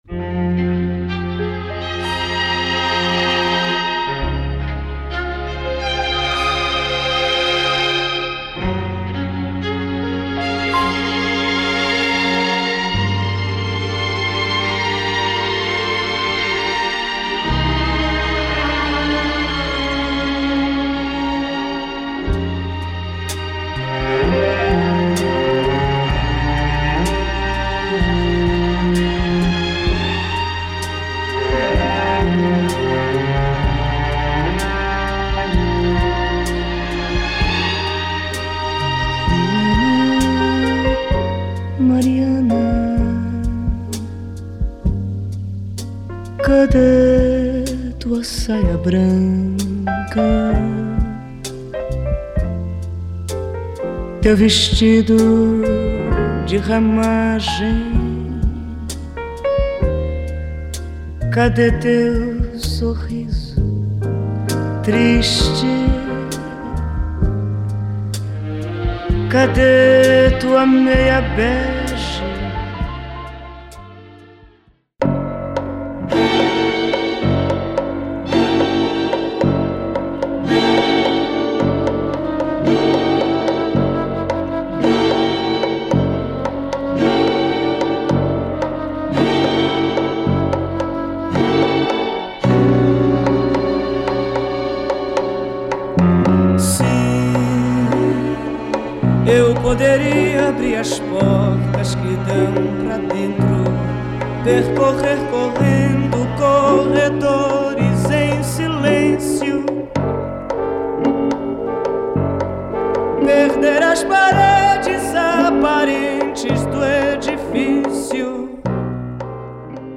Brazilian singer
soft jazz